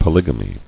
[po·lyg·a·my]